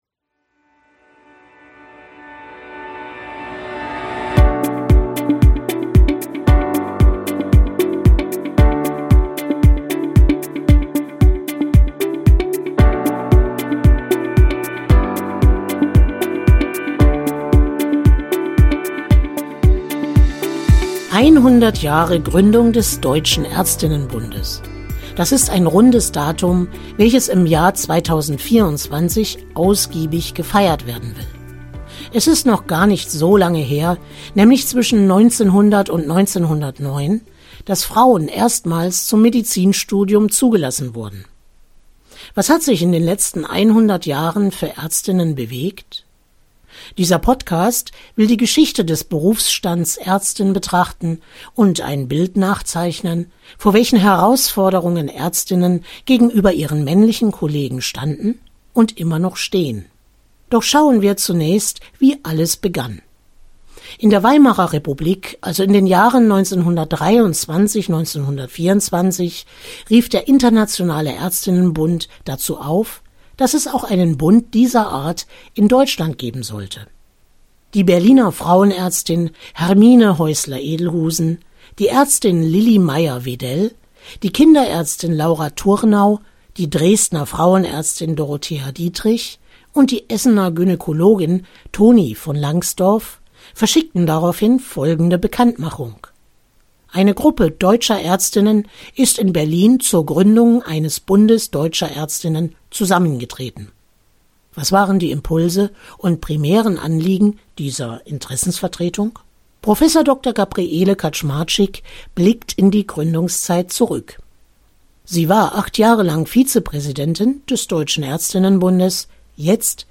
Begegnen Sie in diesem dreiteiligen Podcast Frauen, die die Positionen des Ärztinnenbundes prägen und Expertinnen, die von außen auf die Themen blicken. Erkunden Sie in Folge 1 die Vergangenheit, beispielsweise die Bedeutung von Ärztinnen im Nationalsozialismus, die Angst vor der sexuell befreiten Frau oder die vertanen Chancen der Wendezeit.